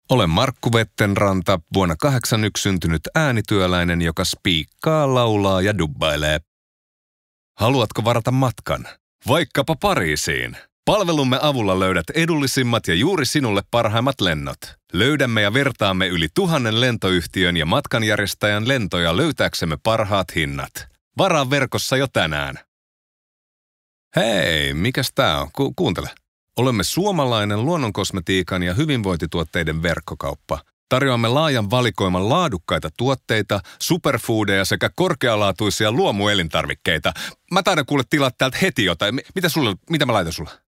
Voice color: deep